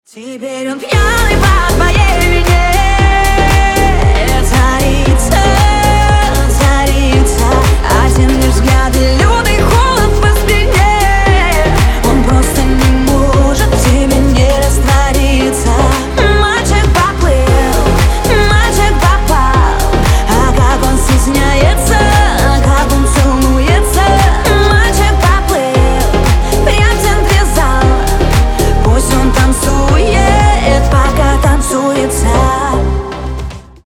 поп
зажигательные , красивый женский голос
танцевальные